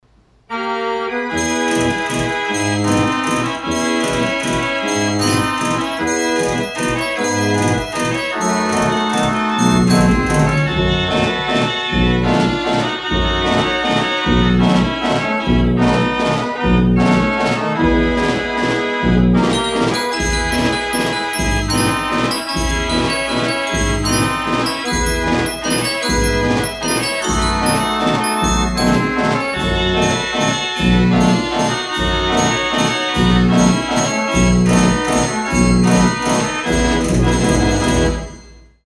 Carousel Fair Organ
her music is very exhilarating